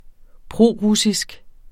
Udtale [ ˈpʁoˌʁusisg ]